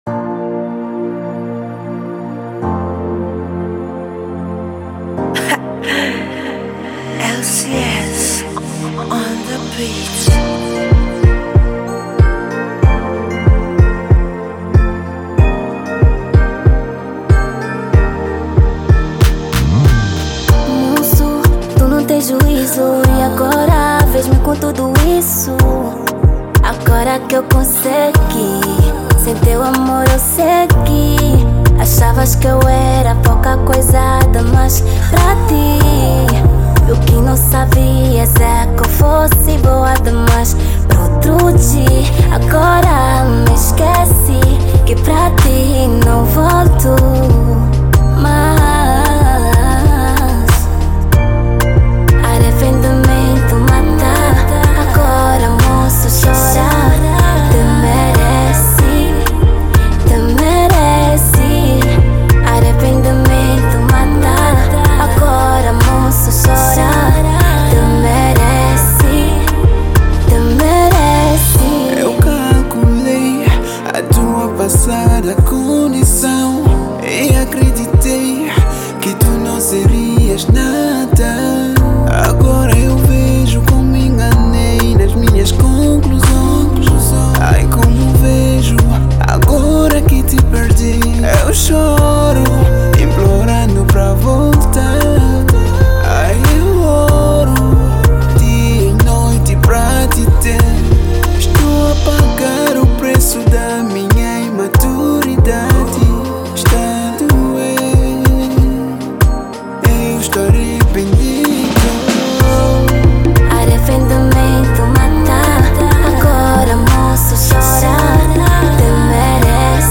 | Afro Classic